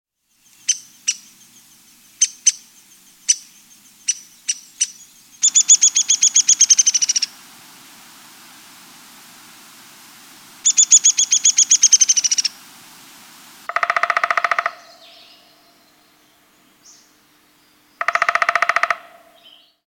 Downy Woodpecker
downywoodpecker.mp3